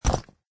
stone3.mp3